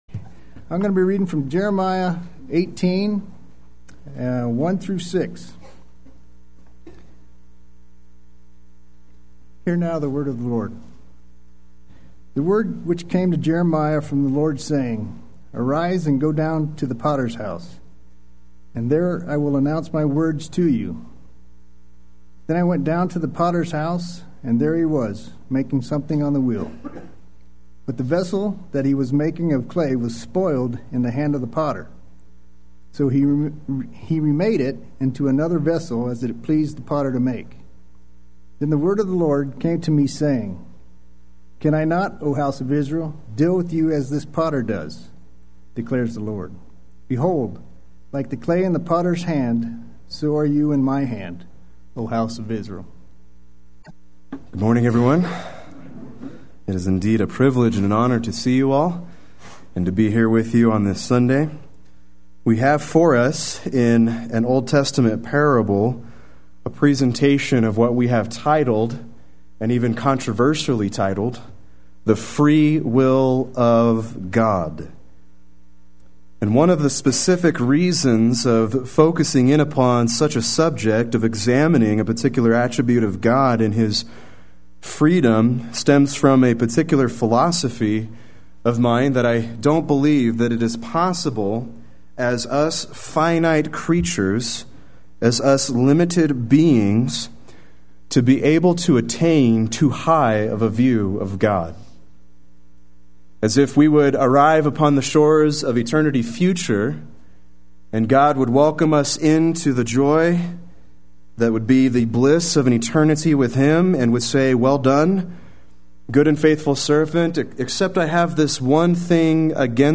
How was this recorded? The Free-Will of God Sunday Worship